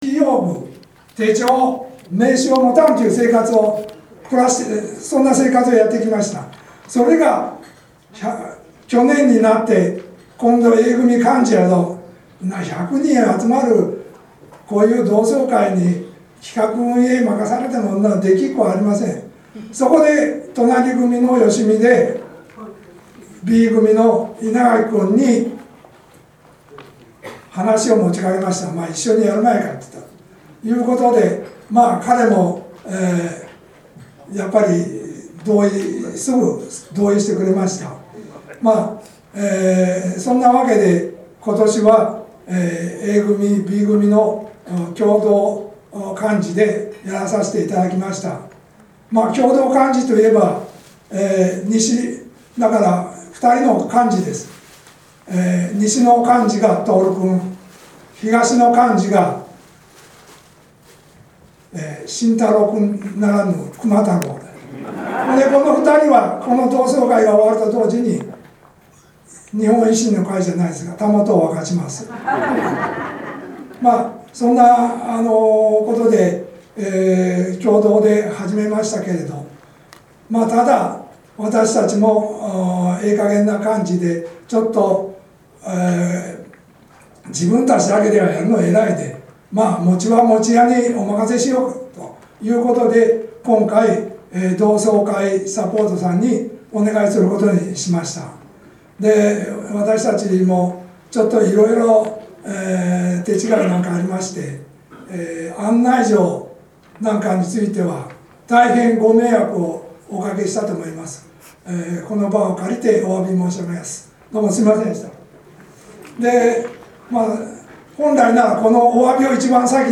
同窓会(H26)
開催責任者挨拶.mp3